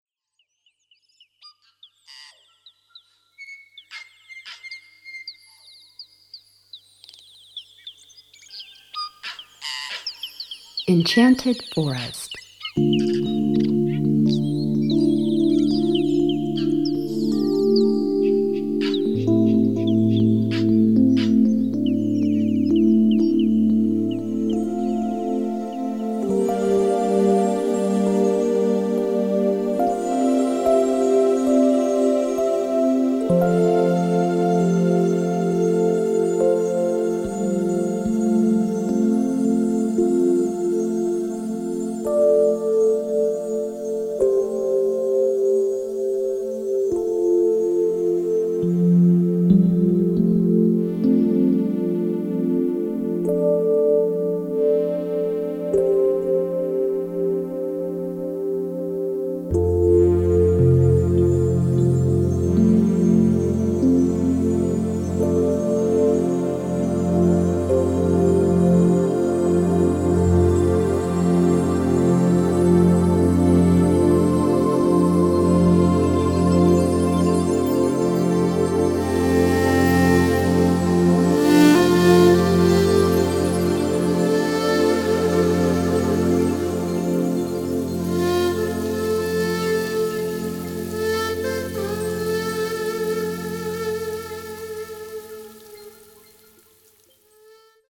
Relaxační a Meditační hudba
Verbální vedení: Neverbální